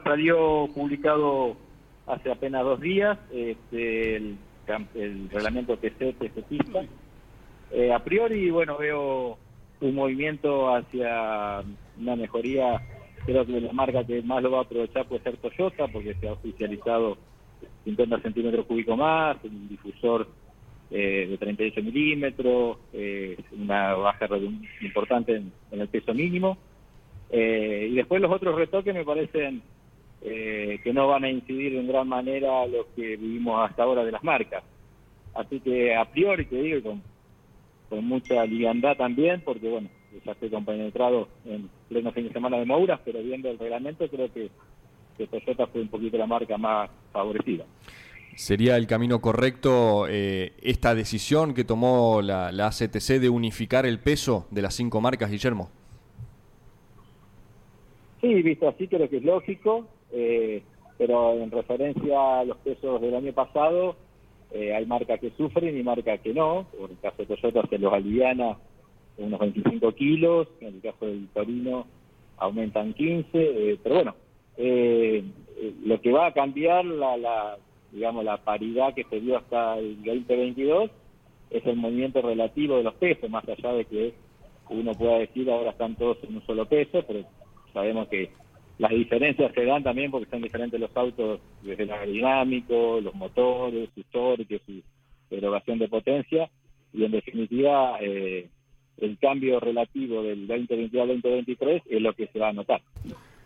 en diálogo con Campeones Radio